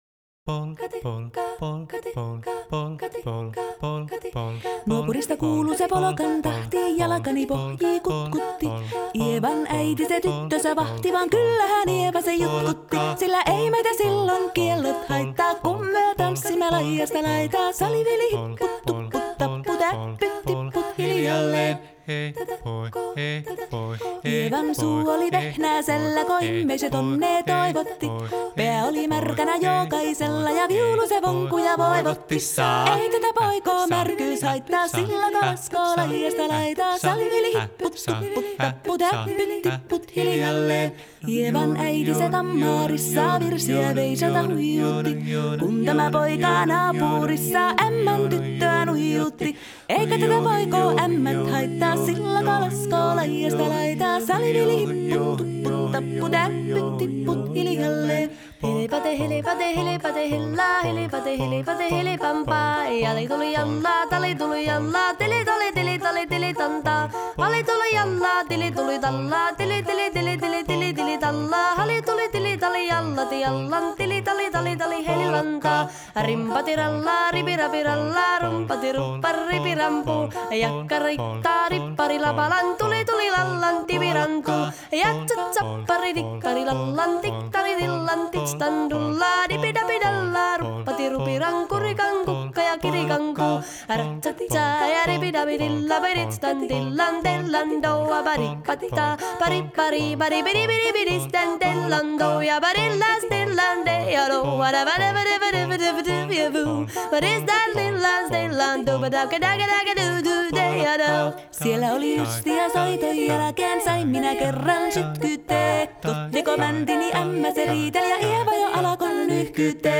Finnish dialect: Eastern Savonian